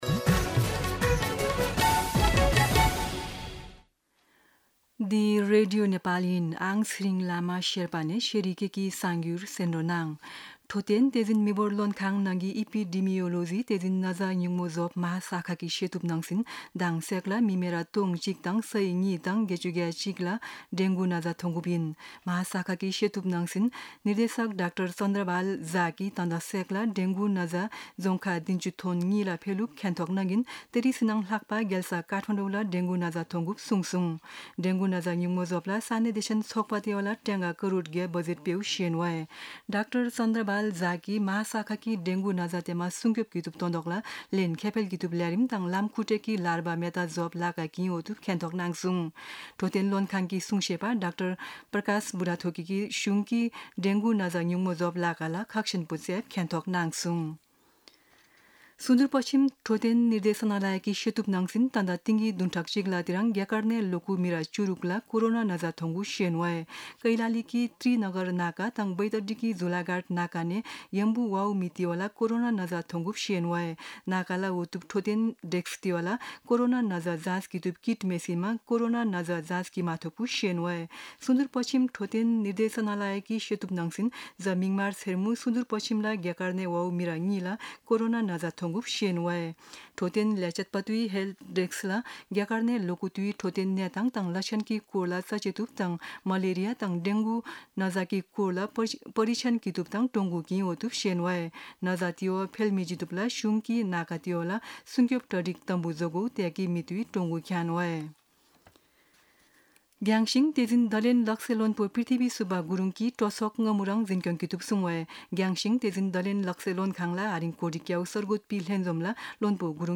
शेर्पा भाषाको समाचार : २८ जेठ , २०८२
Sherpa-News-2-28.mp3